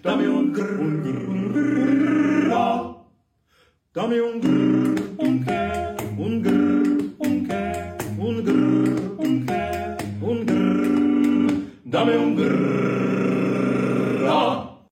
dame un grrrrr Meme Sound Effect